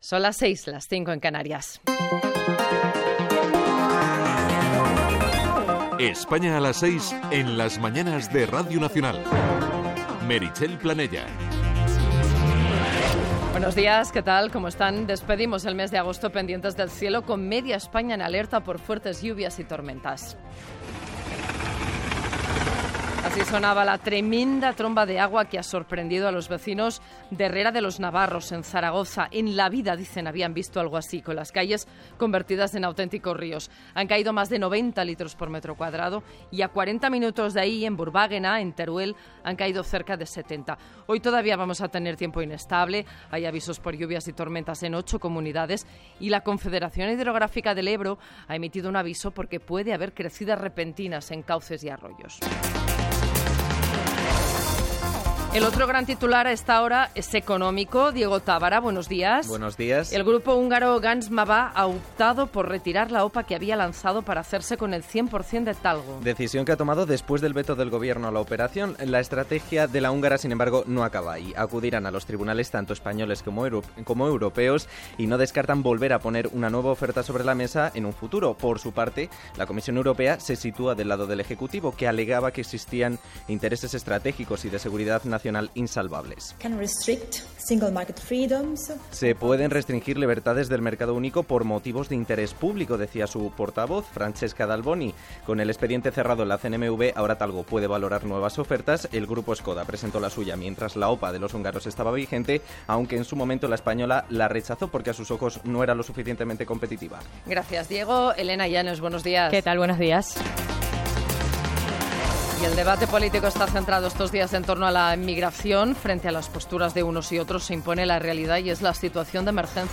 Informatiu
DAB